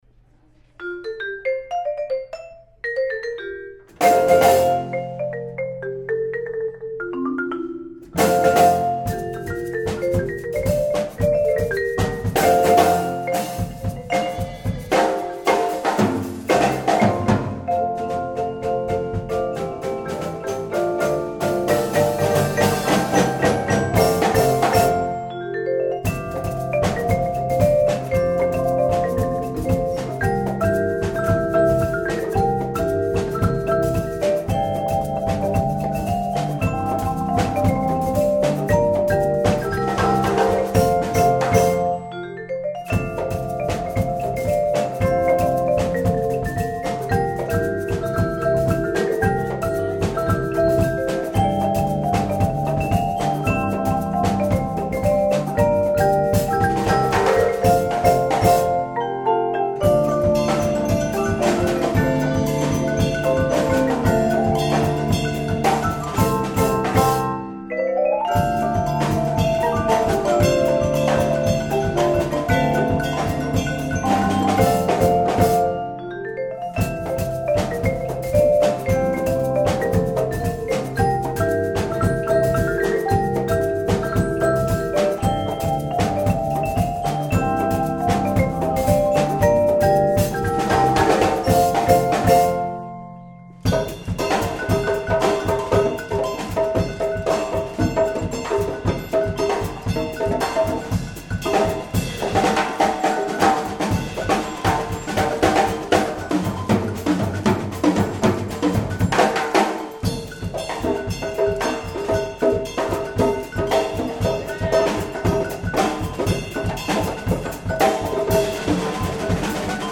Genre: Percussion Ensemble
# of Players: 8 + Optional Electric Bass
Bells
Vibraphone 1 (3-octave)
Vibraphone 2 (3-octave)
Marimba 1 (4-octave)
Marimba 2 (4.3-octave)
Congas
Drum Set
Optional Electric Bass